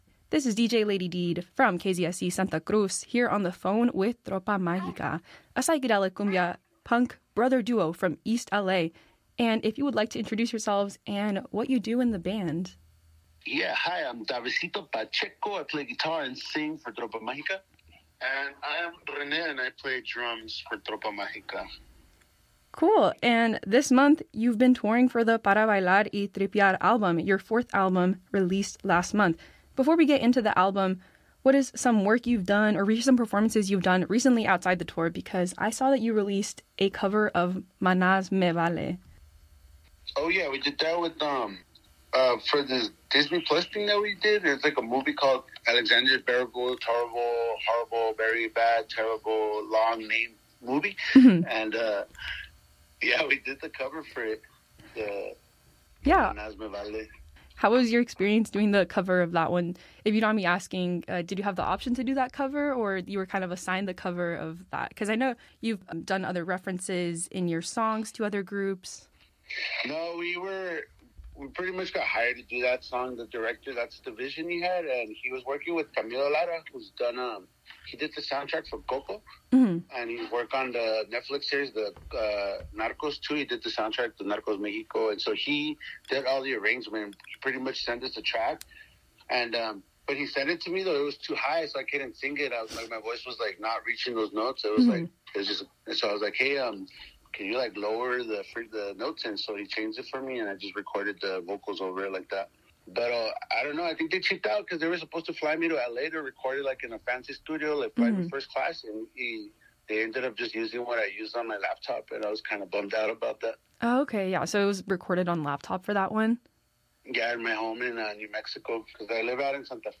KZSC On The Phone With Tropa Magica - Interviews | KZSC Santa Cruz